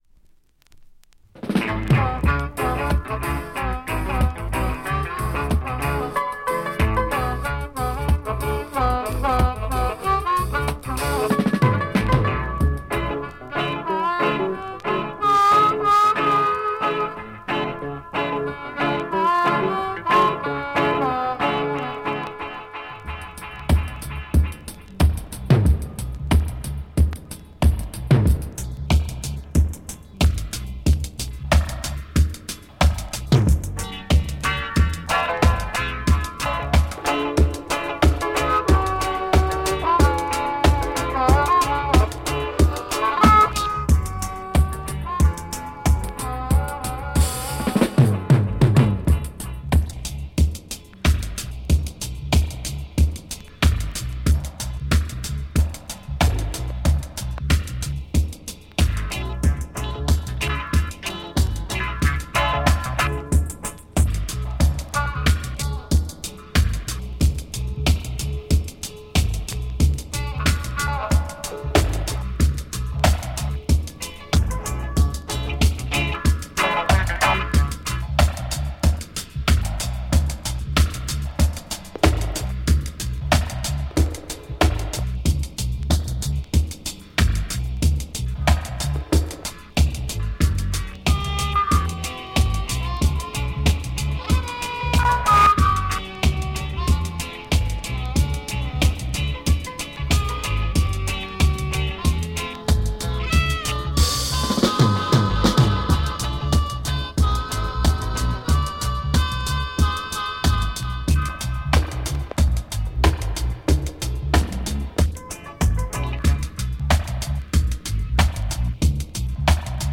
Reggae dub break